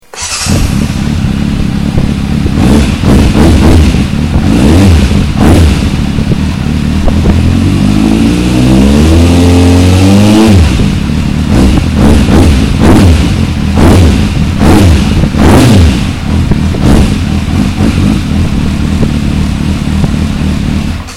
12) Laděný výfuk.
zvuk.......